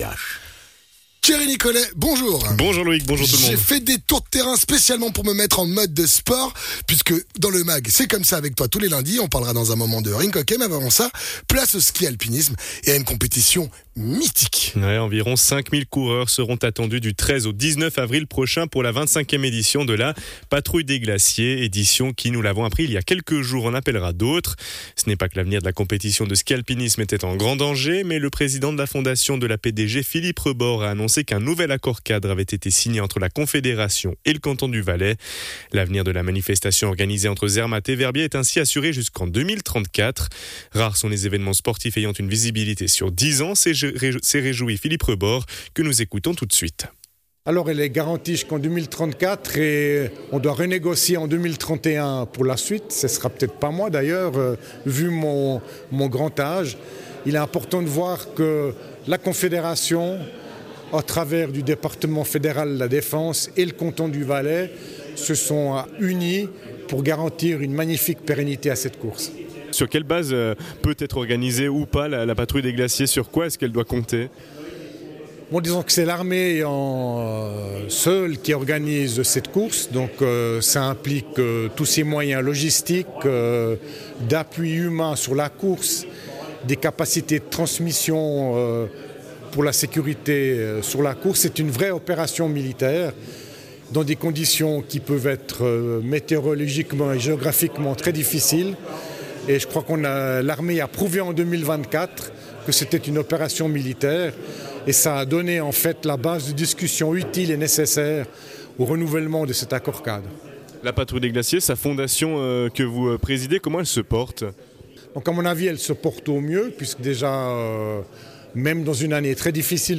Philippe Rebord (président de la Fondation PdG)